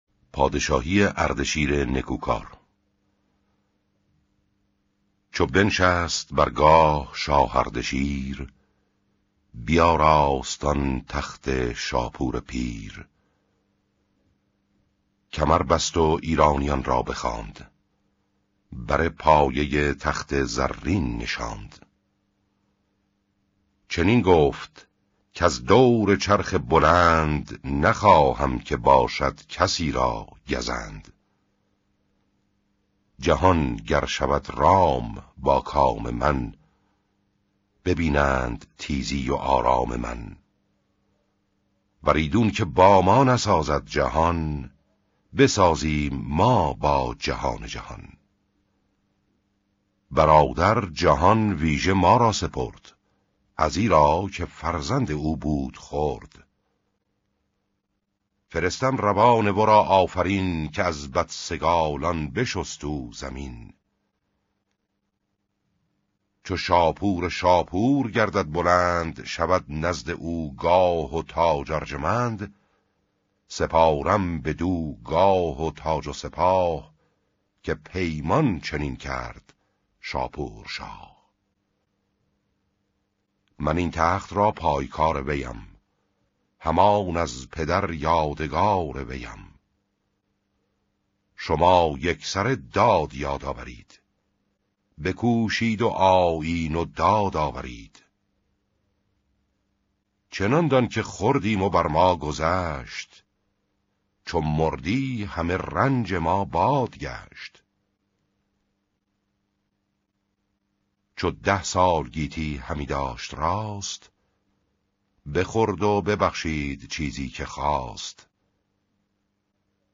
اردشیر نیکوکار :: شاهنامه صوتی